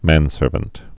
(mănsûrvənt)